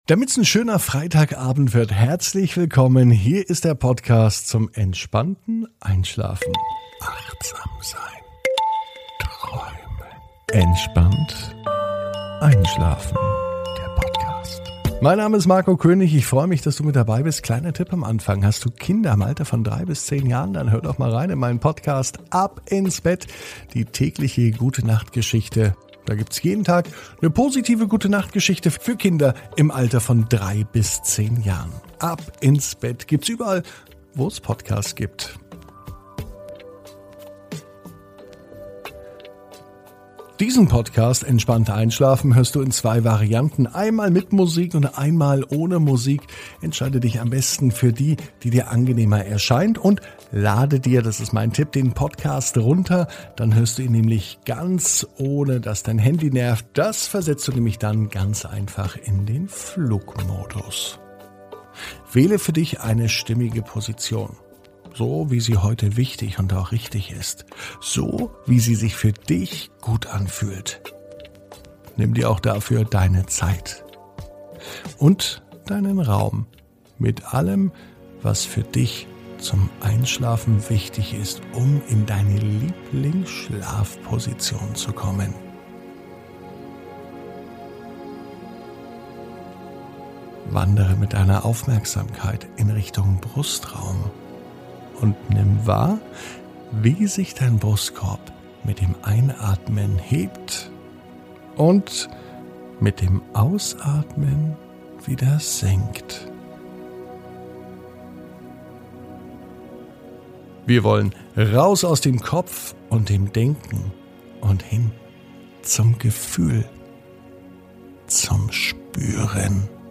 Heute startet der neue Einschlafpodcast Entspannt einschlafen.